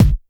Kick_99.wav